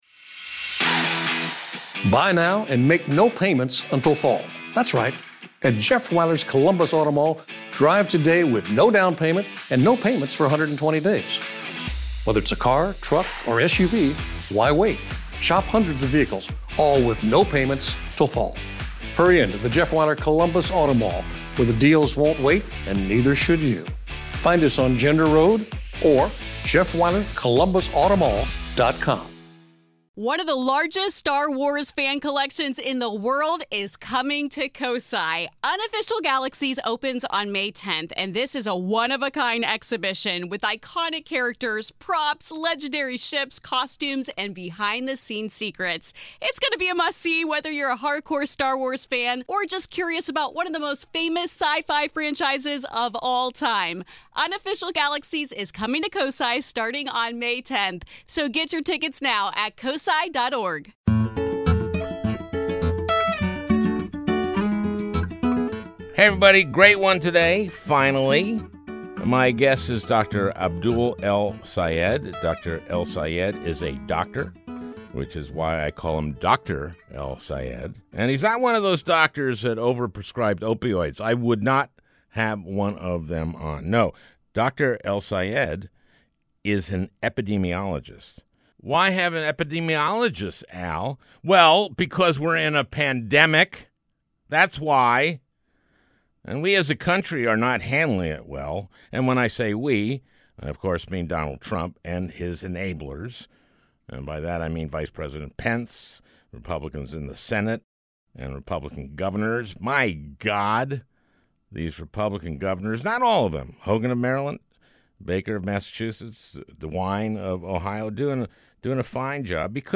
Al and Former Detroit Public Health Commissioner on the Mistakes Trump Keeps Making.